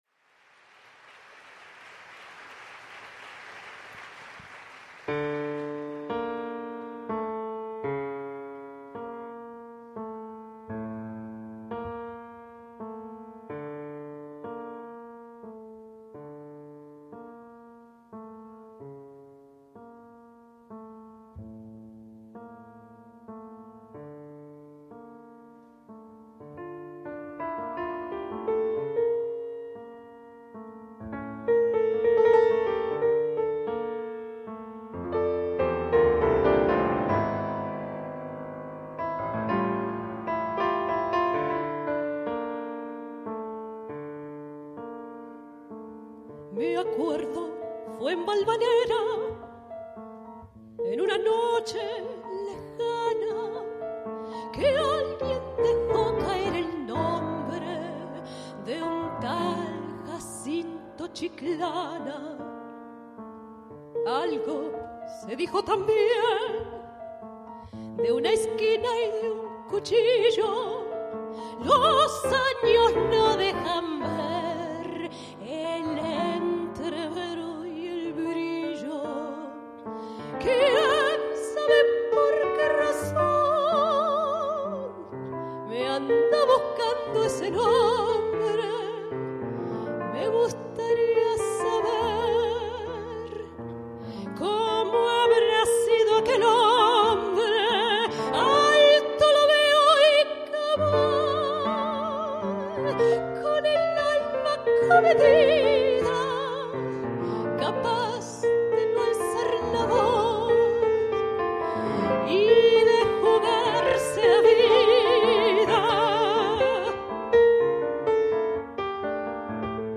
pianoforte.
live recording